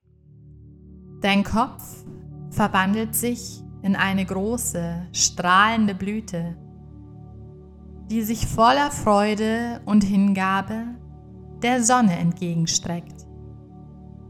Unsere geführte Sonnenblumen-Meditation lädt Dich ein, Dich tief mit der spirituellen Essenz der Sonnenblume zu verbinden.
kraeuterweihe-lughnasadh-sonnenblumen-meditation-2024-hoerprobe.mp3